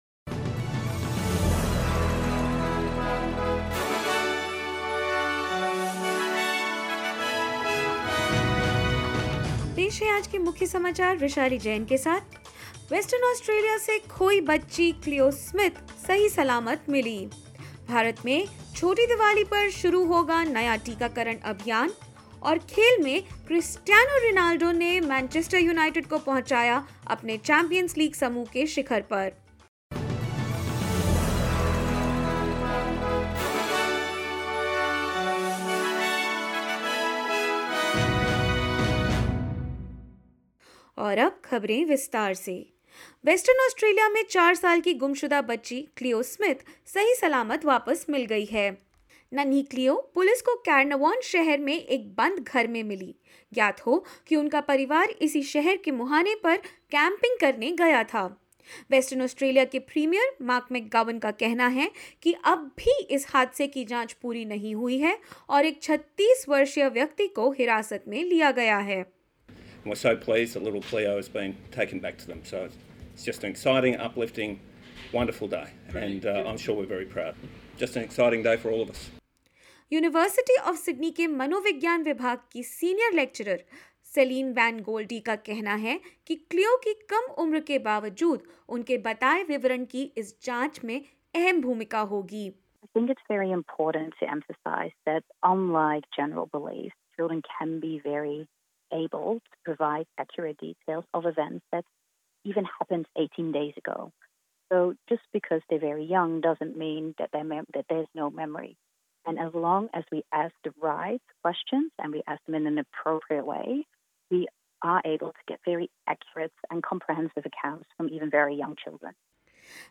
In this latest SBS Hindi news bulletin of Australia and India: NSW Premier Dominic Perrottet orders a review into how grants are handed; Federal Labour Party leader Anthony Albanese greets the community on Diwali from Victoria and more.